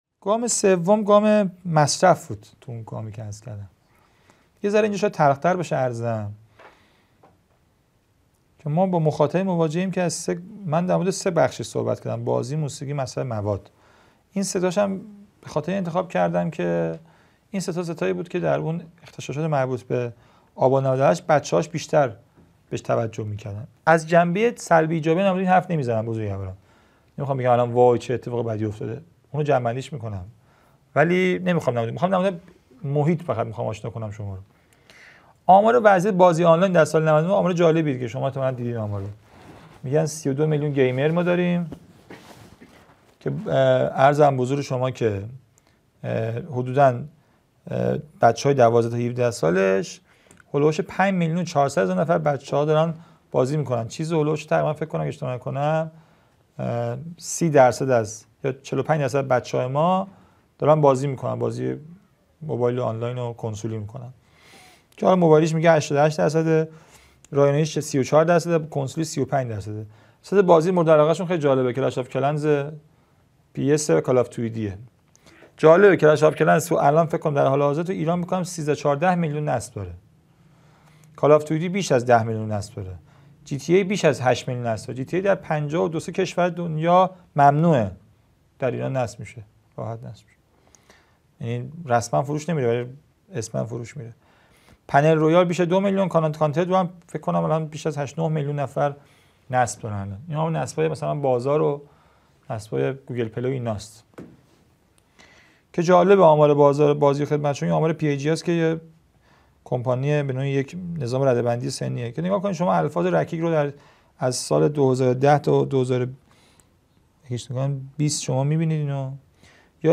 گزیده چهاردهم از دومین سلسله نشست‌ های هیأت و نوجوانان - با موضوعیت نوجوان در ایران